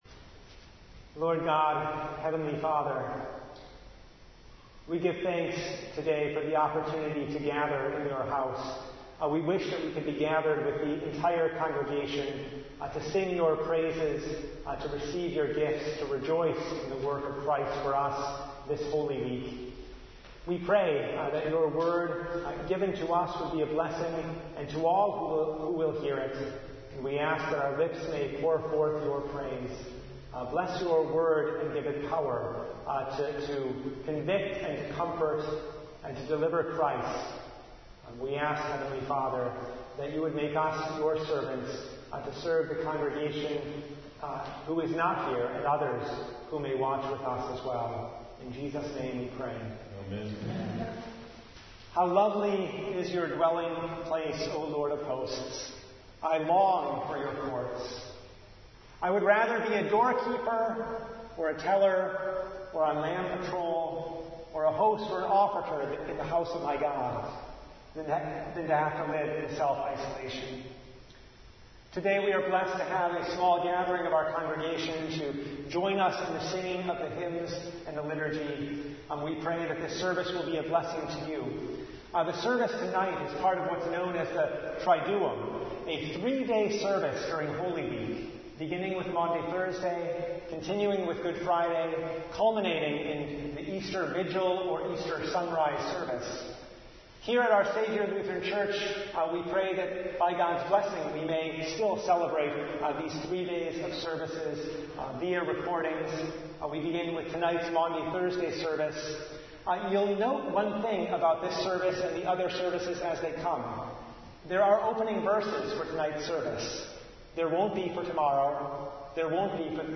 John 13:1 Service Type: Maundy Thursday Though our hearts are turned in on ourselves
Topics: Full Service « At the Heart of It All—His Love!